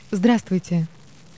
- він навіть вміє розмовляти: